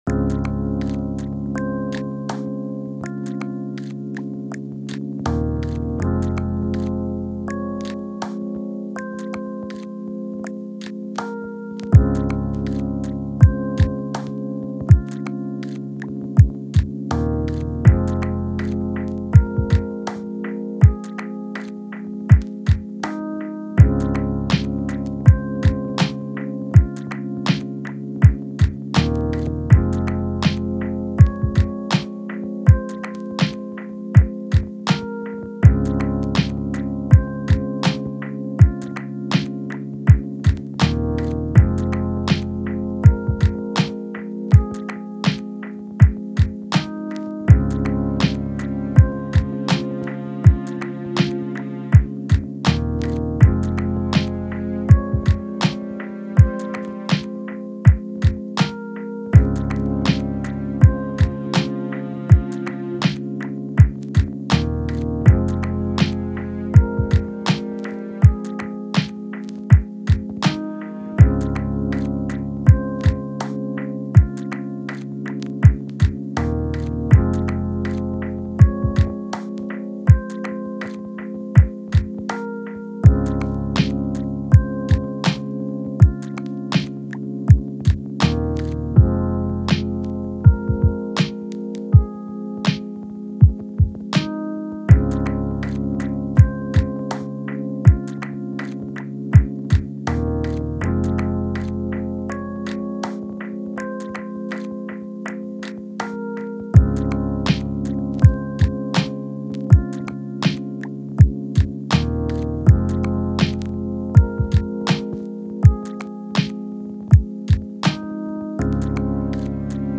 Agradecimientos de fondo musical a